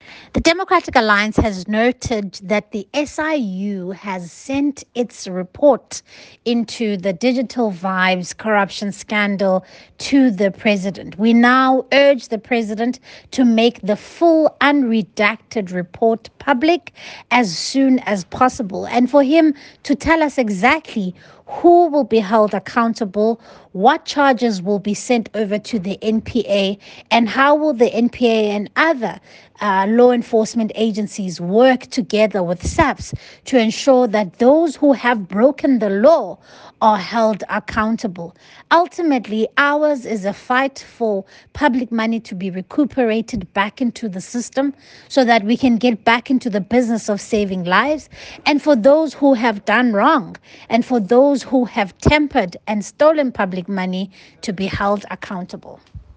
soundbite by Siviwe Gwarube MP.